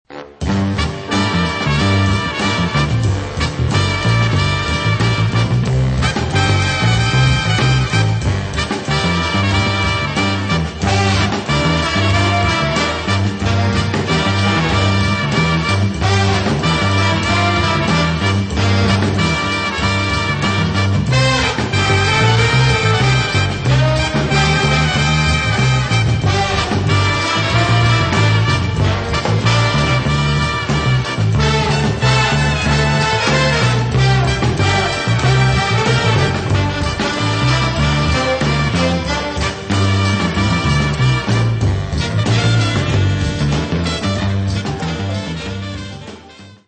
22 groovy tv & movie themes from 1969-1984